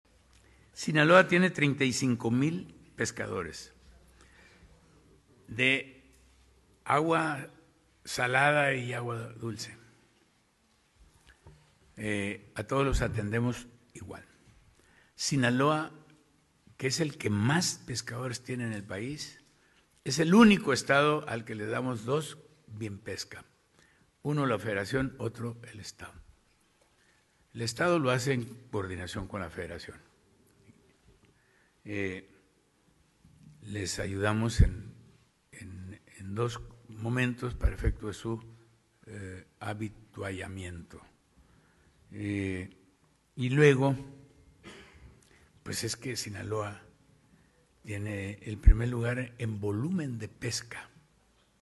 Guasave, Sinaloa, a 19 de enero de 2026.– Durante la conferencia “La Semanera”, encabezada por el gobernador del estado, Dr. Rubén Rocha Moya, la titular de la Secretaría de Pesca y Acuacultura (SPyA), Flor Emilia Guerra Mena, informó la apertura del periodo de ventanillas para diversos programas de apoyo al sector pesquero y acuícola, a partir de este lunes 19 de enero: